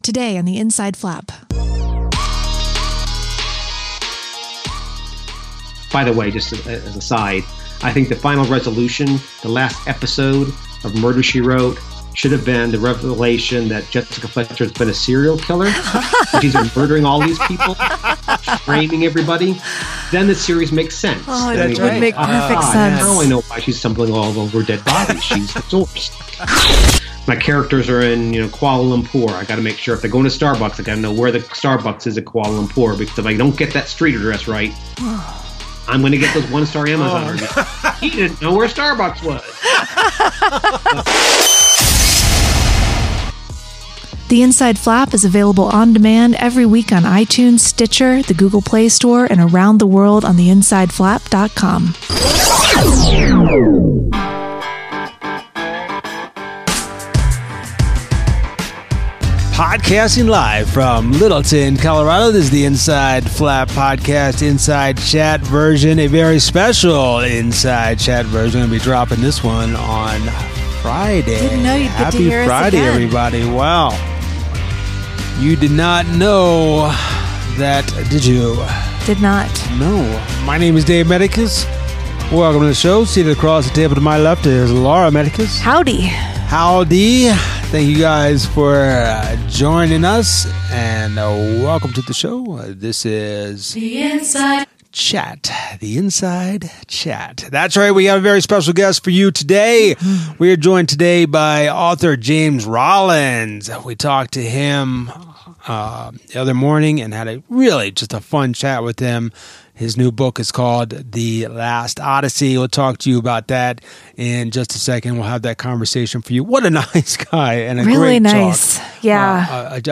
An “Inside Chat” with Author James Rollins who talks with us about his fantastic new novel The Last Odyssey, a lice laden tarantula, playing with mythology, and the Jessica Fletcher Syndrome. Plus – candy thermometers and blasting music.